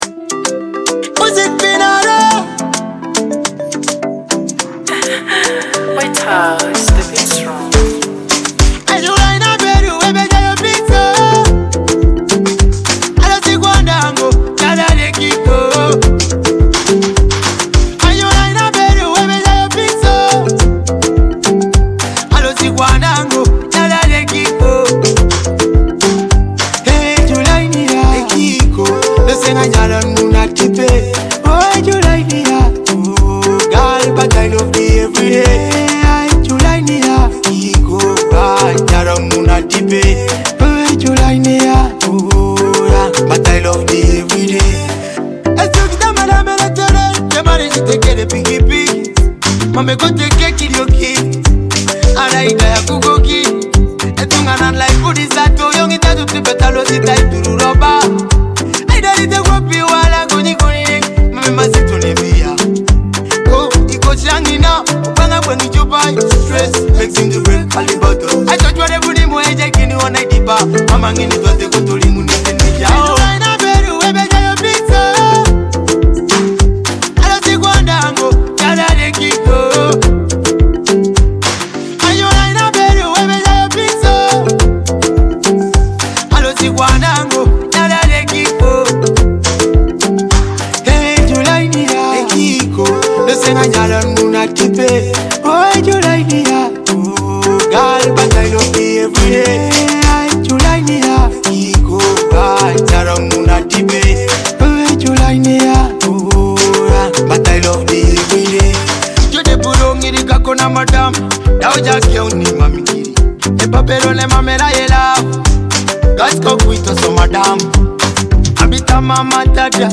an inspiring Teso song filled with emotion
Through heartfelt lyrics and smooth rhythms